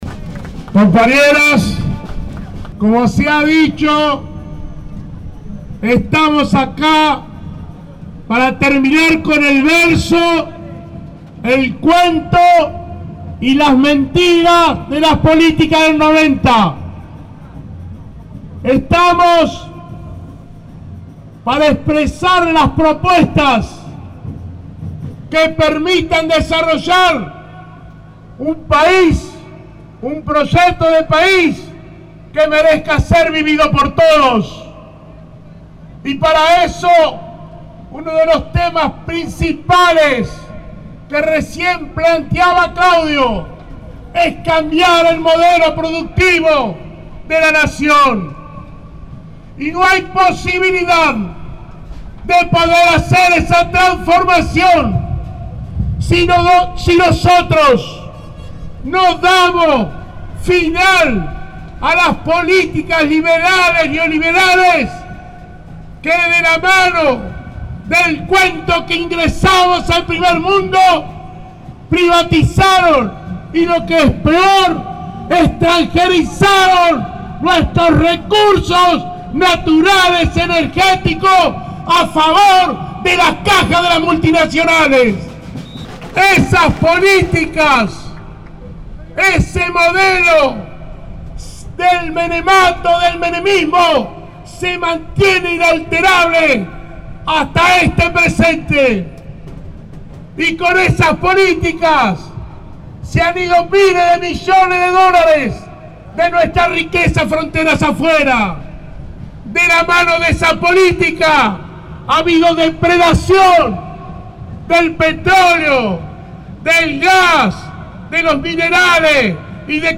CTA - Central de los Trabajadores Argentinos - Marchamos el 17 de diciembre/09 en Capital Federal